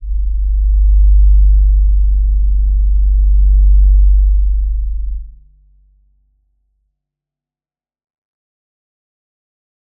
G_Crystal-G1-pp.wav